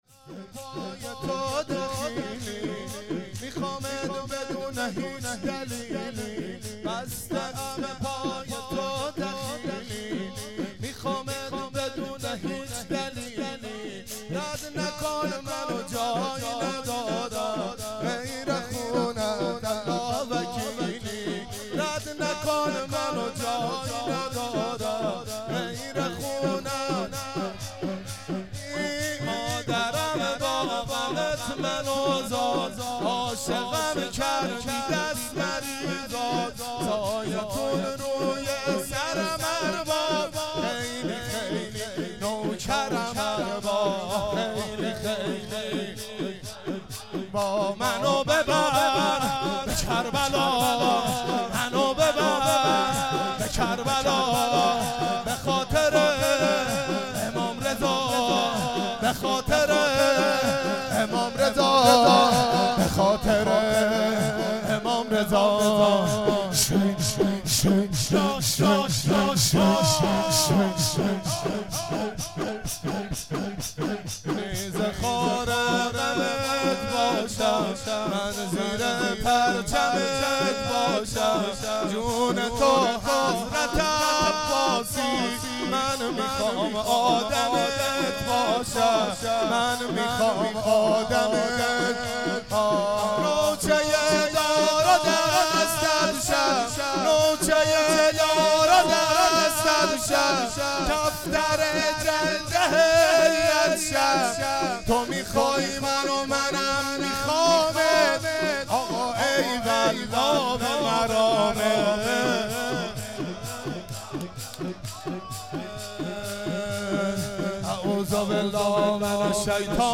شور،بستم به پای تو دخیلی